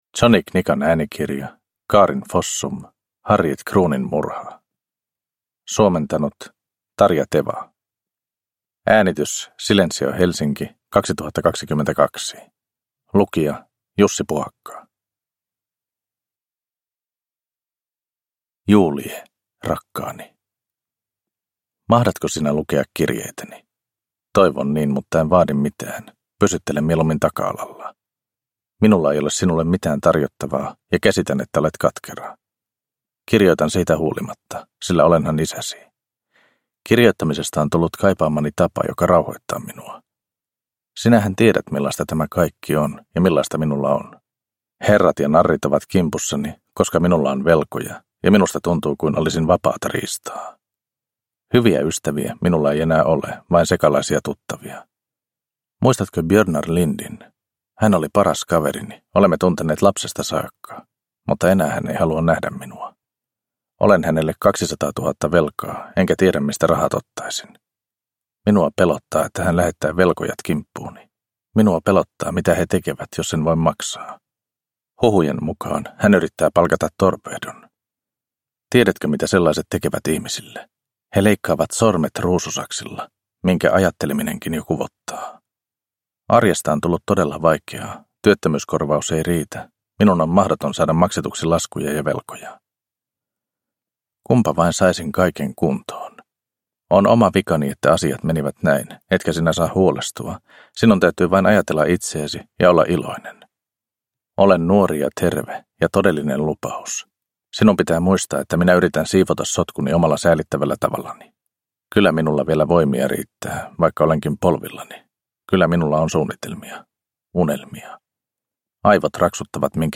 Harriet Krohnin murha – Ljudbok – Laddas ner